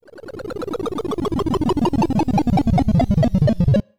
Zoom Down FX 001.wav